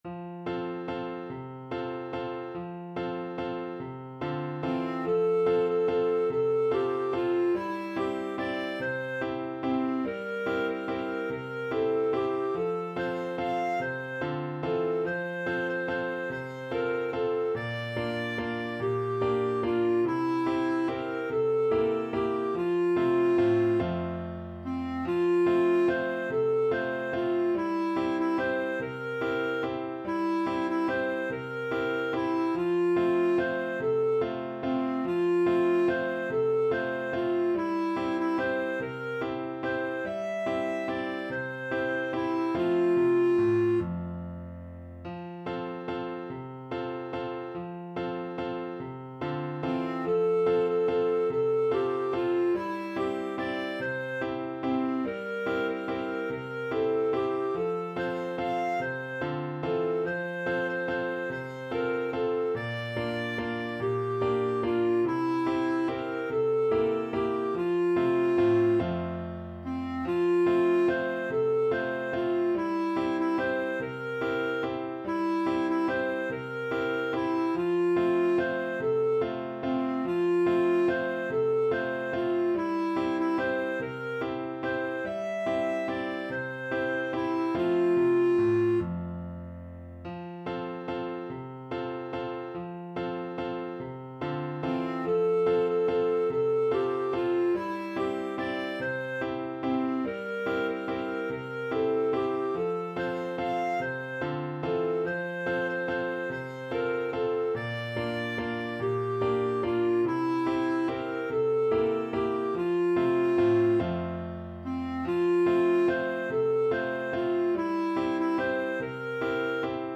Steady one in a bar .=c.48
3/8 (View more 3/8 Music)